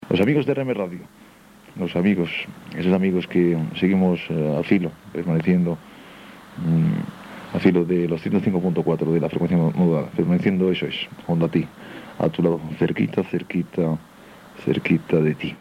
Identificació